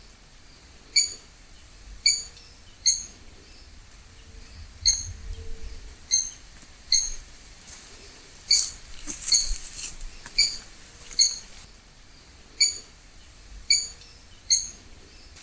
Hemitriccus griseipectus
Maria-de-barriga-branca-11.10.2025.mp3
Nombre en inglés: White-bellied Tody-Tyrant
Condición: Silvestre
Certeza: Observada, Vocalización Grabada